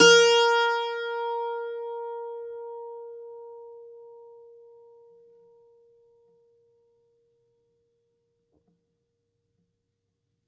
STRINGED INSTRUMENTS
52-str10-bouz-a#3.wav